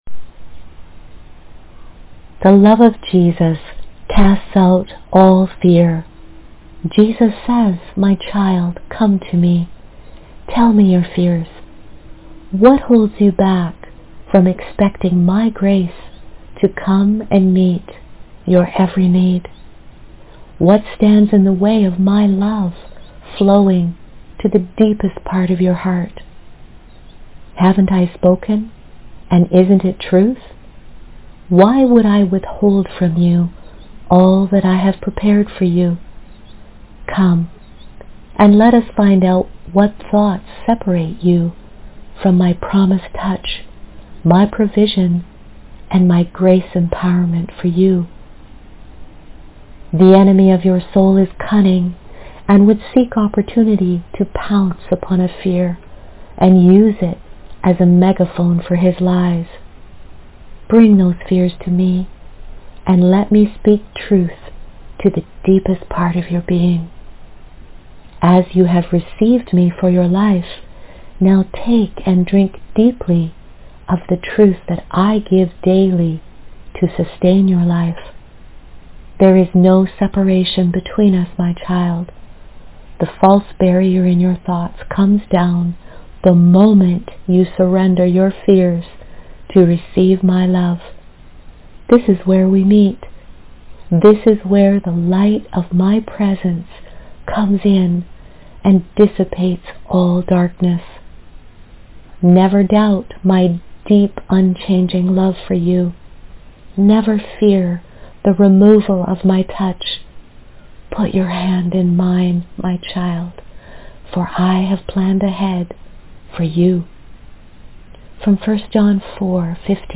Audio prayer and verse :